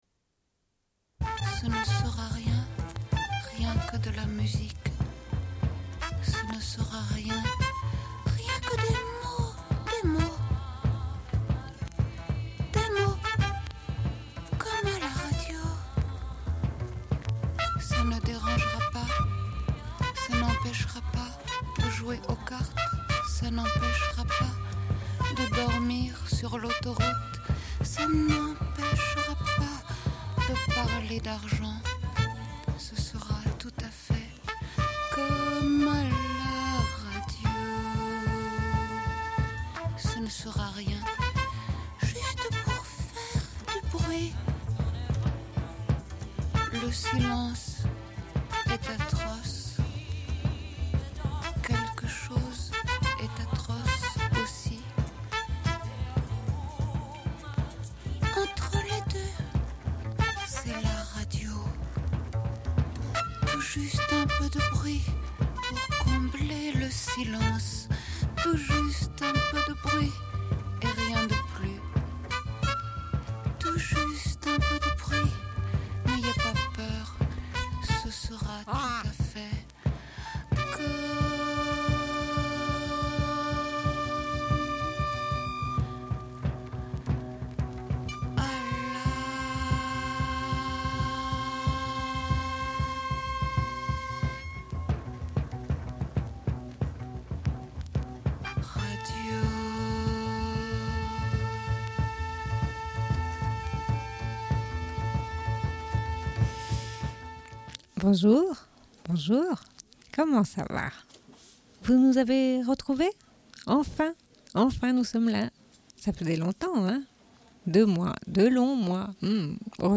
Bulletin d'information de Radio Pleine Lune du 04.09.1991 - Archives contestataires
Une cassette audio, face B00:28:43